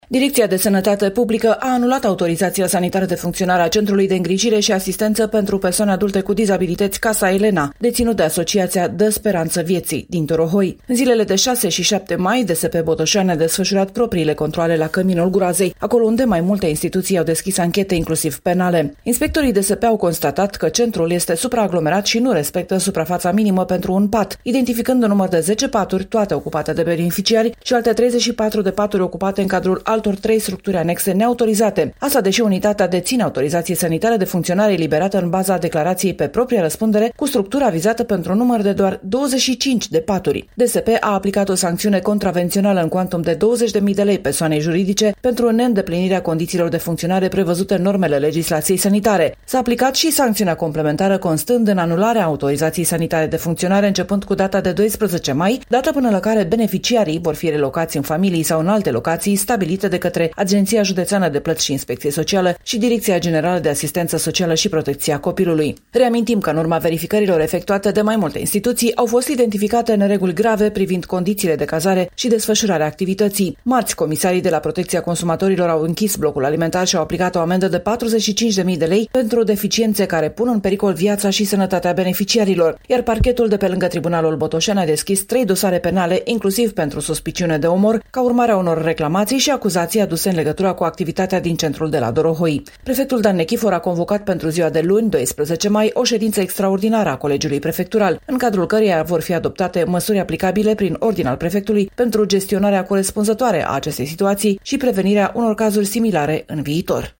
Mai multe ne spune corespondenta noastră